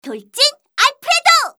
academic_f_voc_skill_machadrive_a.mp3